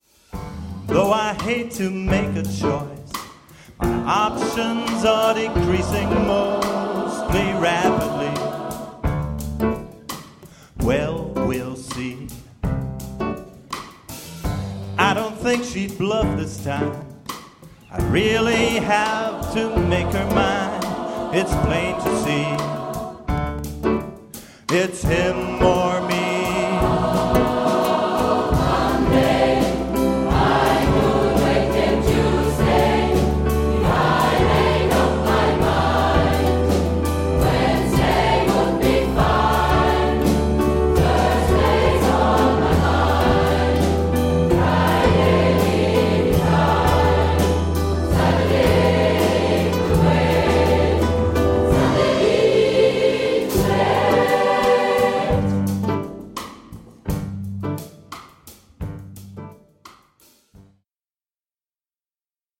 05:00 Speziell: 5/8-Takt Link YouTube